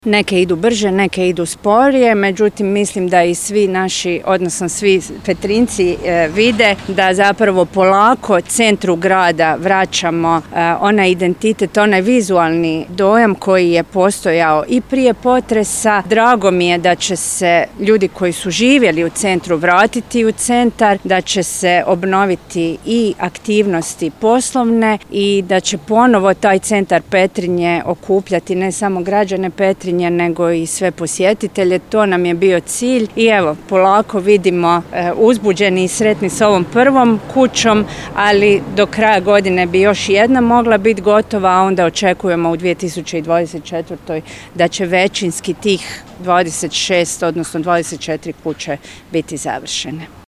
Obuljen Koržinek navodi kako je 21 zgrada u realizaciji